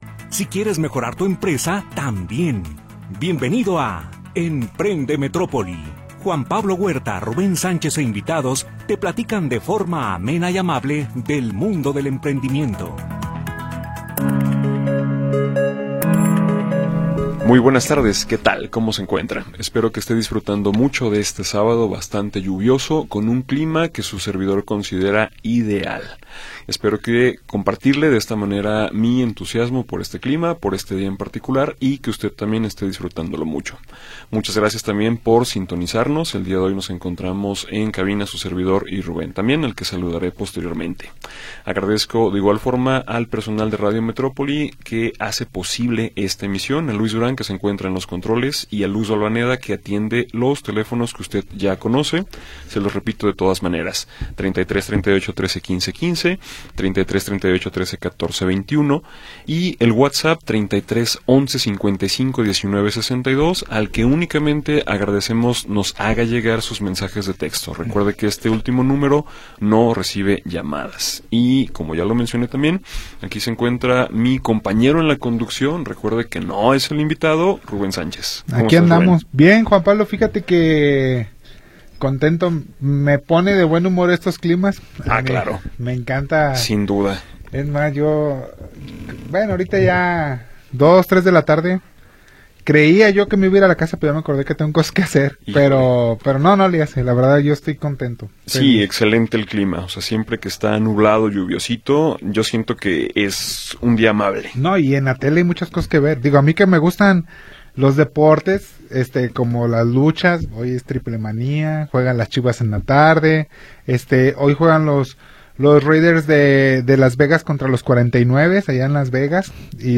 e invitados te platican de forma amable y amena acerca del mundo del emprendimiento.